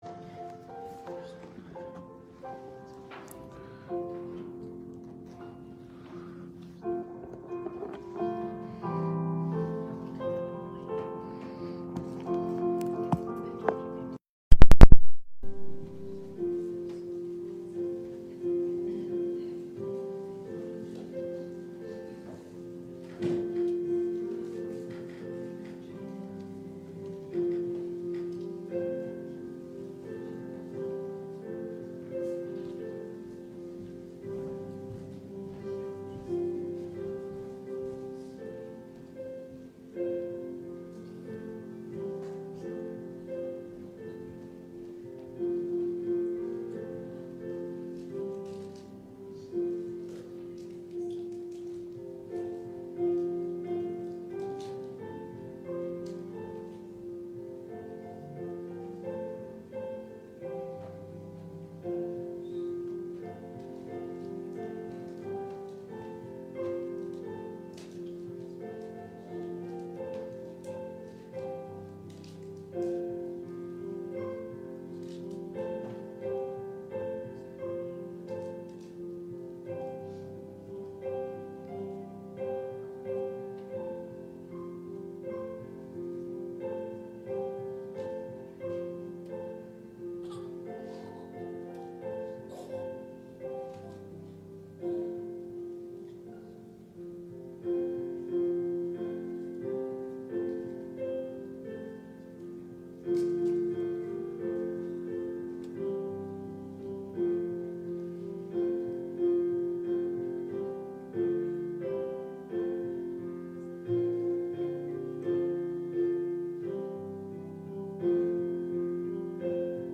Living Life in A Fallen World | SermonAudio Broadcaster is Live View the Live Stream Share this sermon Disabled by adblocker Copy URL Copied!